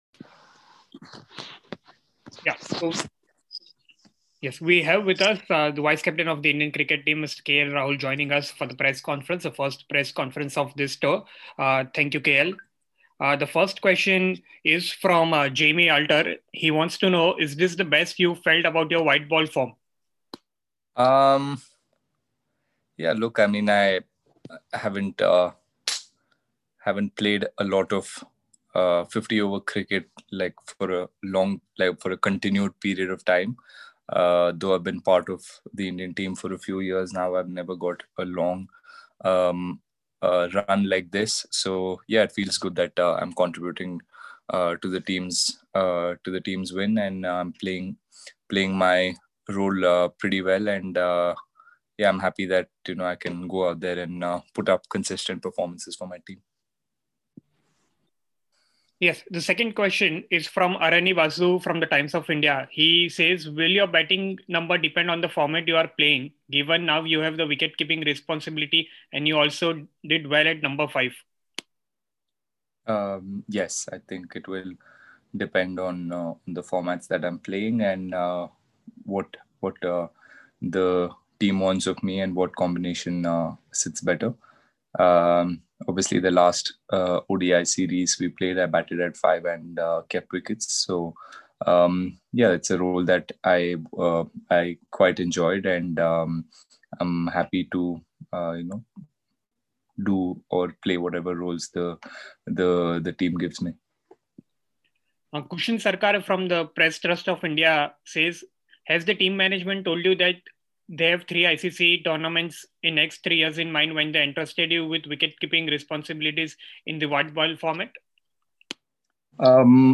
Team India Vice-Captain Mr KL Rahul addressed a press conference on Wednesday, ahead of the first ODI against Australia on Friday in Sydney.